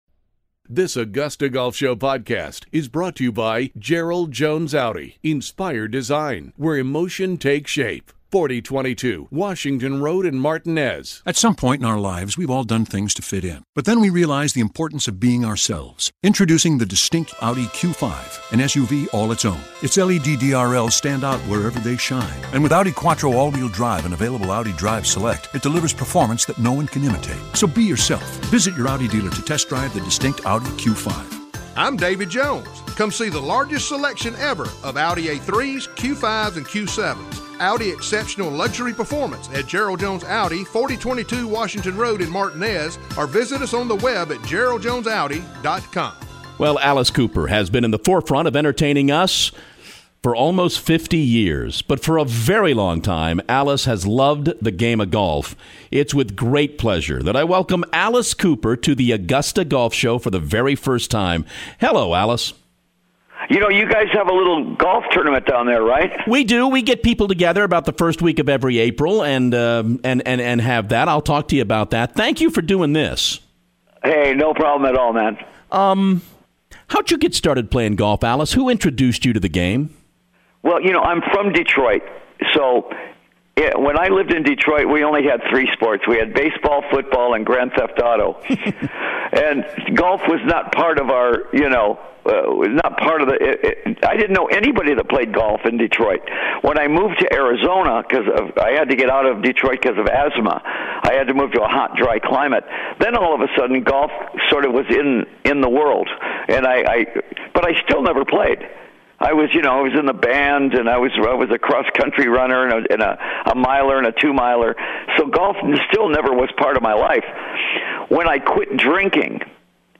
Rock n Roll legend Alice Cooper is on the show to talk about his love of the game, and about why the game of golf has meant so much to him